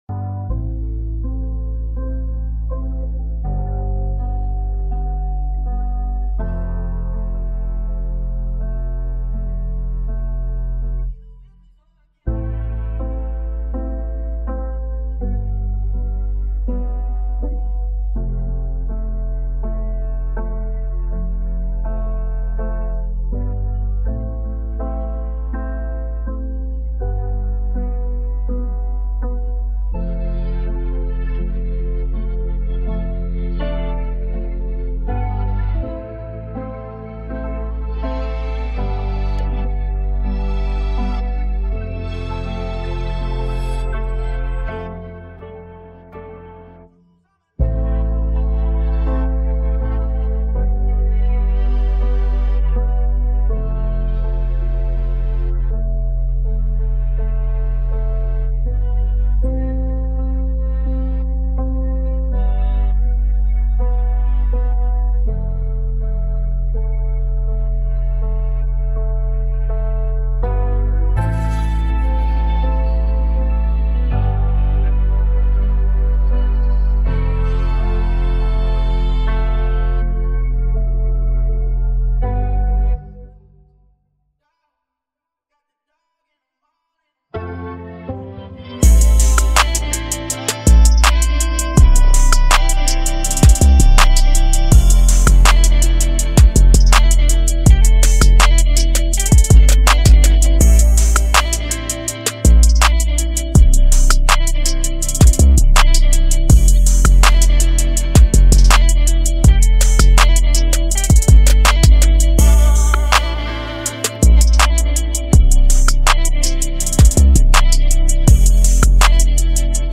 Hip hop hiphop trap beats